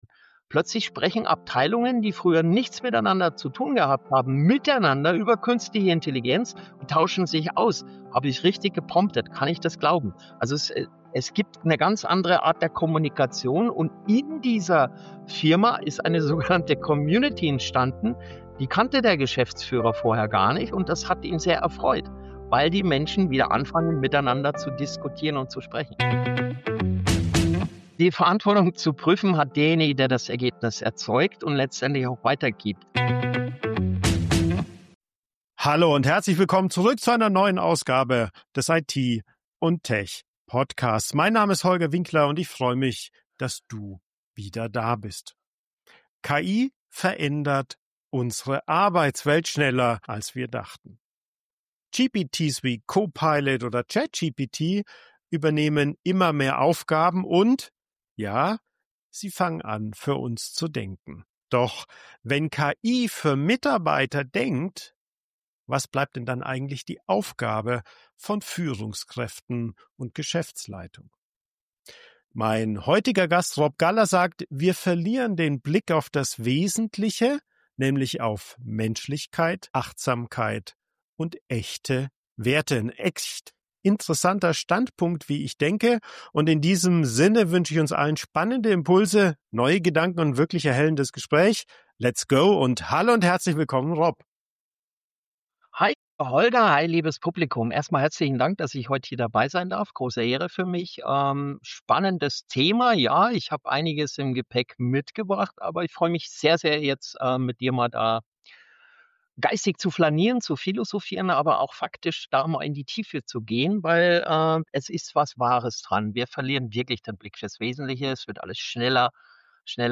Das Gespräch liefert konkrete Impulse, wie Führungskräfte die Technologie sinnvoll einführen und gleichzeitig Vertrauen, Lernbereitschaft und Verantwortung stärken. Warum sollten Sie dieses Interview nicht verpassen?